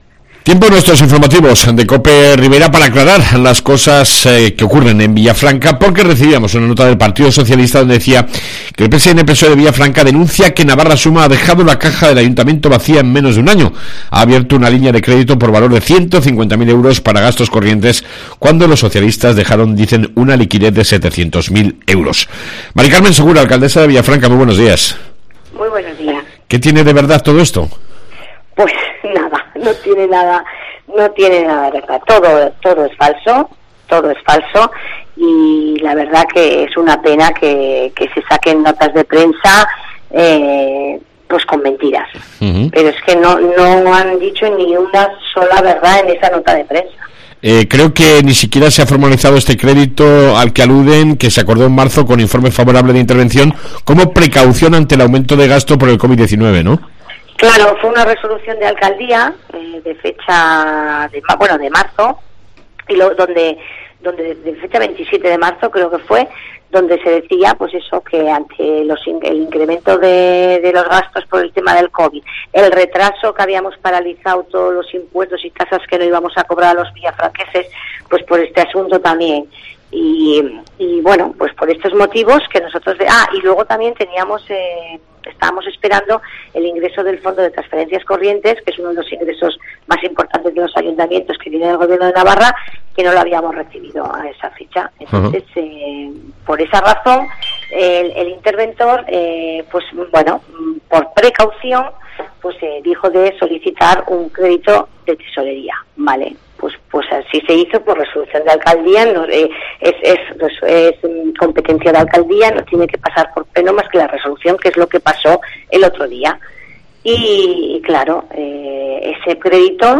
AUDIO: Hablamos con la Alcaldesa de Villafranca sobre la situación financiera del Ayuntamiento.